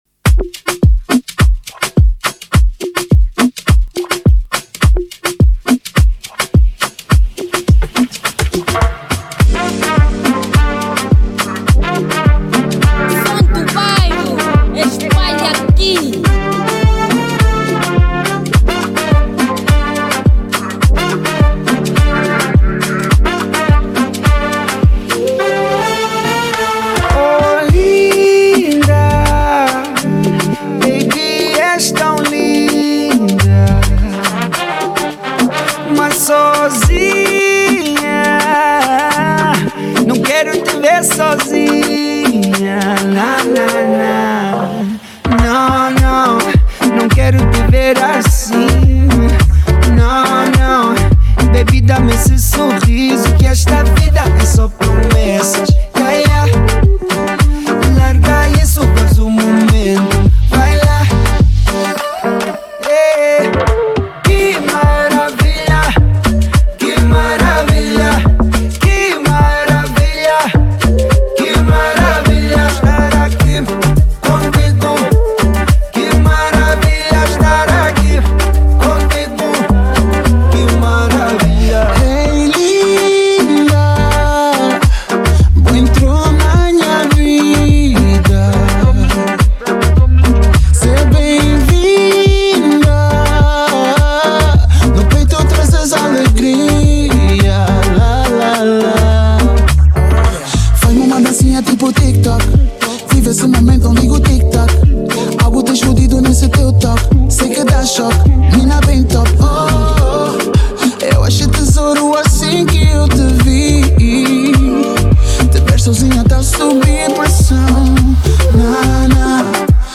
Genero: Afro Beat